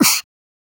Mouth Interface (8).wav